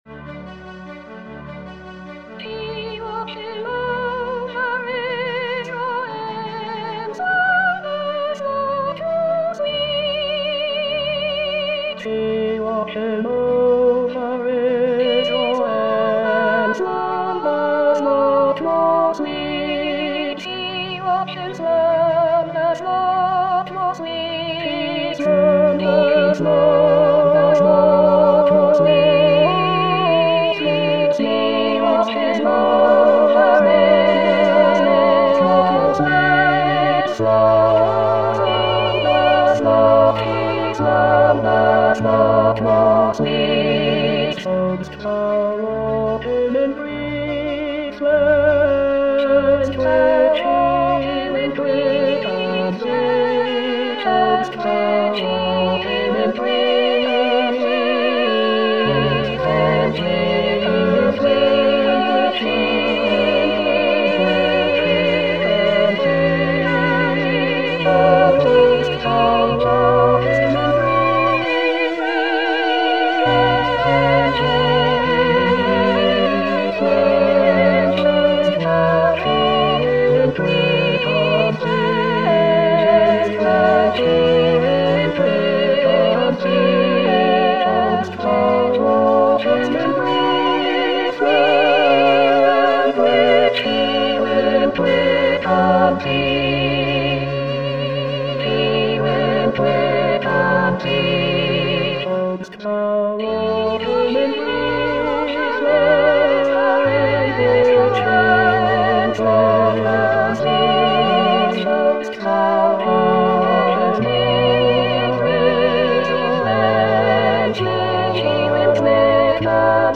Full choir